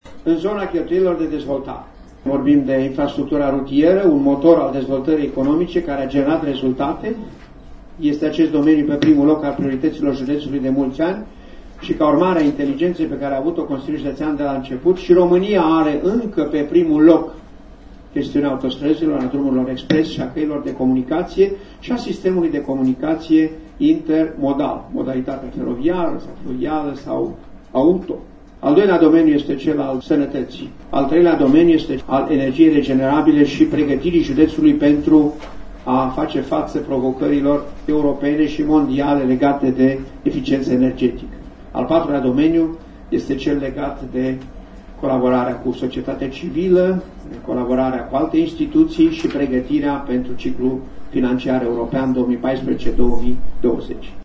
La dezbaterea organizată astăzi la Centrul Cultural din Slobozia, preşedintele Silvian Ciupercă a precizat că principalele obiective de investiţii pe care autoritatea judeţeană şi le propune în 2015 ţin de modernizarea drumurilor şi dotarea spitalului judeţean de urgenţe: